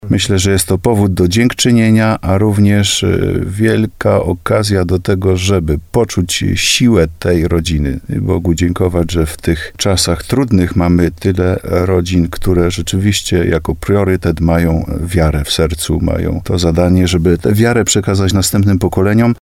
To już tradycja. Rodziny będą świętować w Starym Sączu [ROZMOWA]